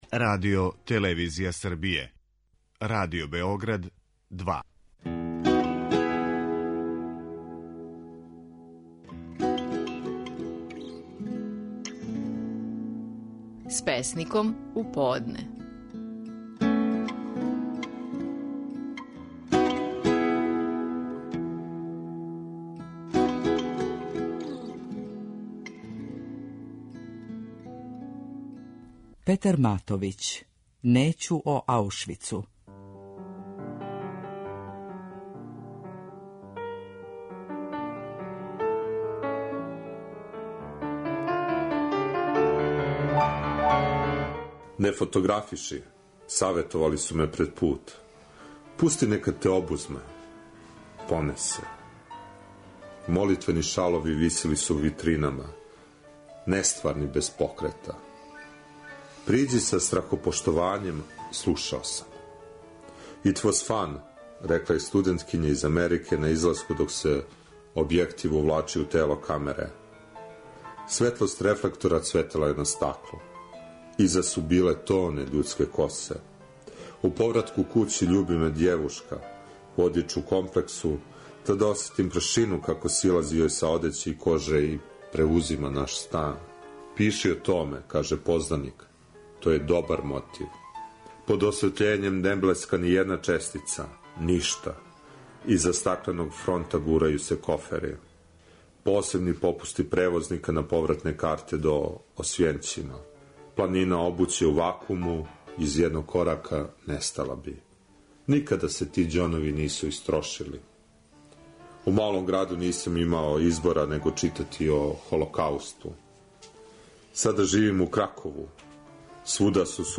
Стихови наших најпознатијих песника, у интерпретацији аутора.
pesnik.mp3